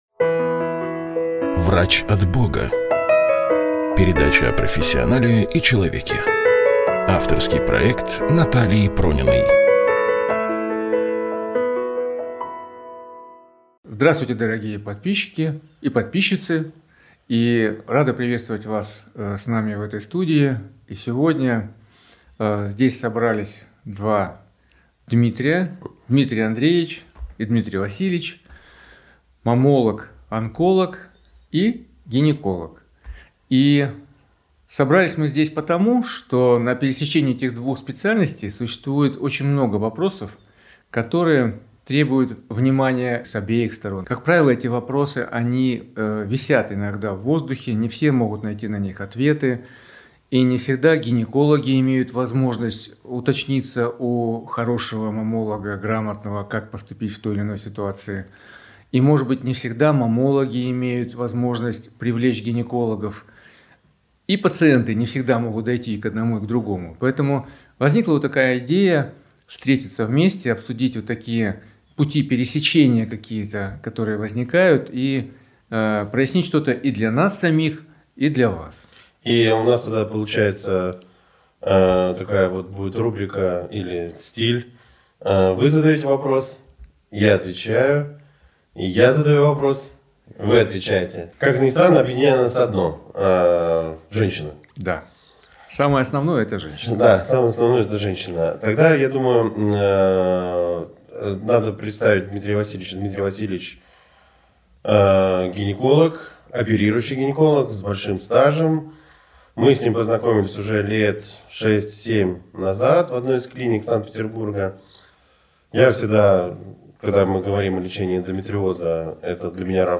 В студии